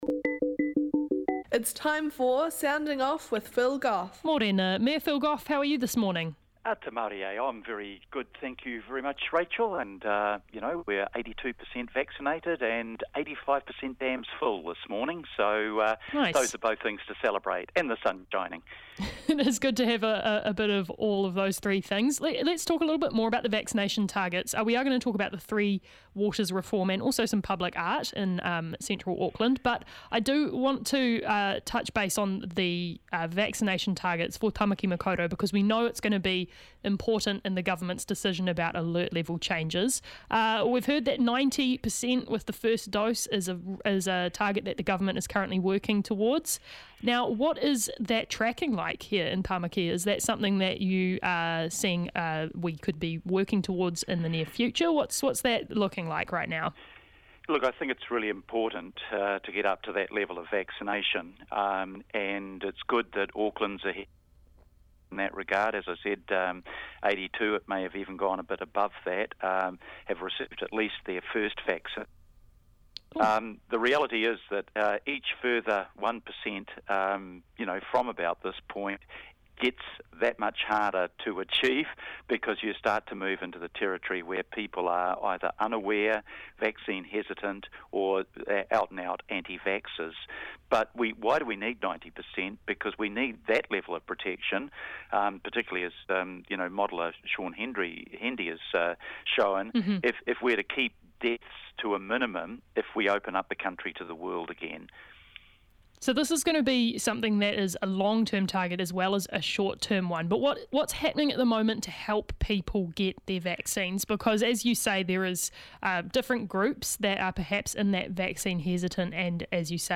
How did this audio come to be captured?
The Mayor is on the line and today he gives us an update on vaccinations in Auckland and the Three Waters Reform.